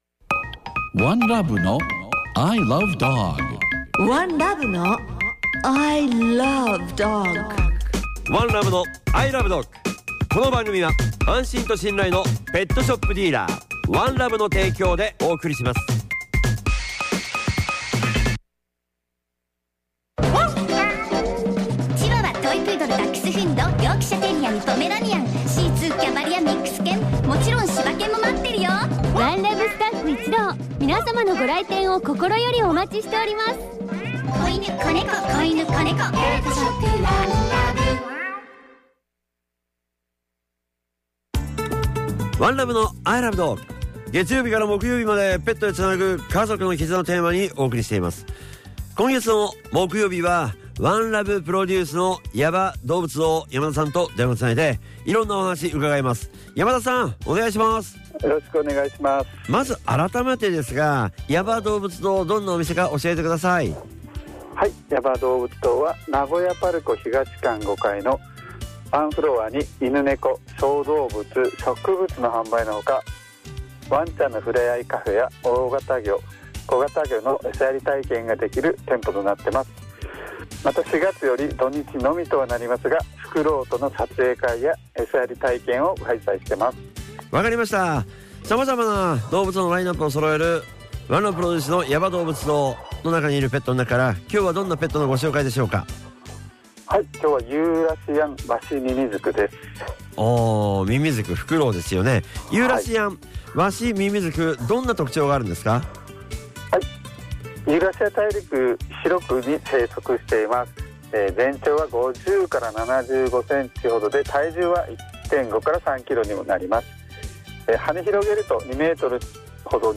水曜の「アーティスト×ペット　絆＆愛」のコーナーでは、アーティストのコメントが聞けるワン！
ゲスト：Girls²